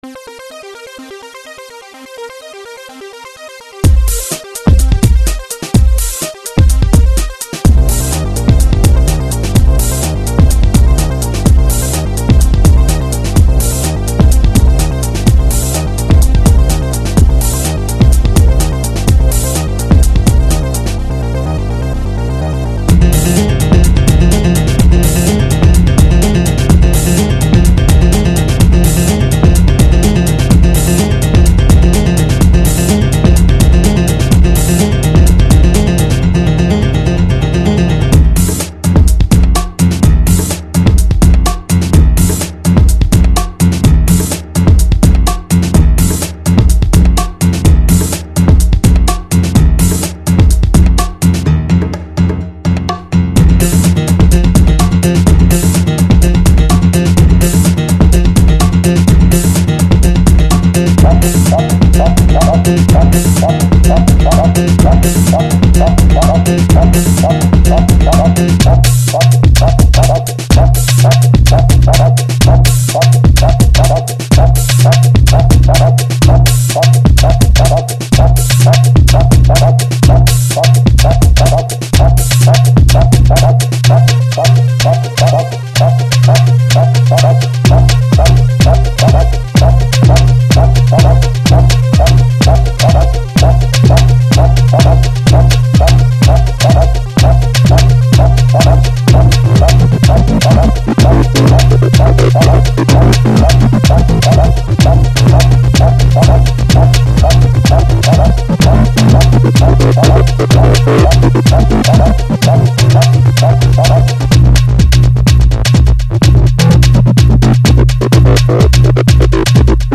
breakbeats~house
D-PRO　LEが後半大活躍しました。